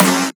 • Original Trap Snare Sound C# Key 119.wav
Royality free snare one shot tuned to the C# note.
original-trap-snare-sound-c-sharp-key-119-aZa.wav